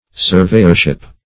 Surveyorship \Sur*vey"or*ship\, n. The office of a surveyor.